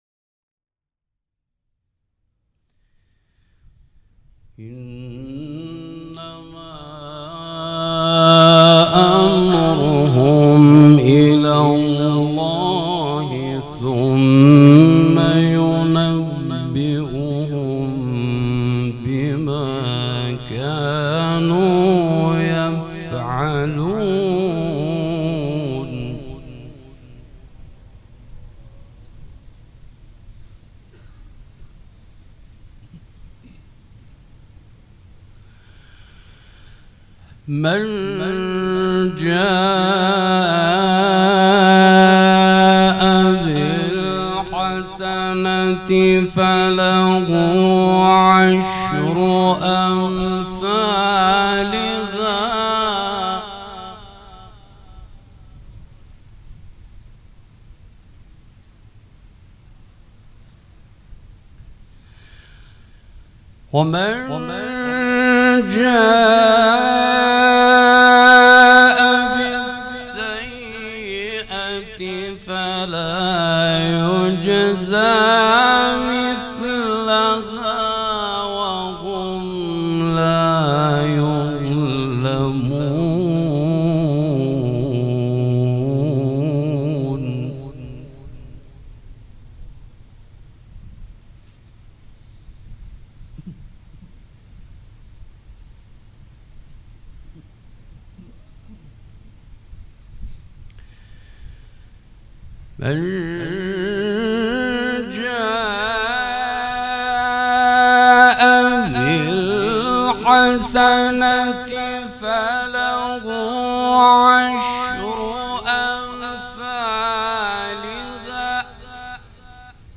قرائت قرآن کریم
مراسم عزاداری شب سوم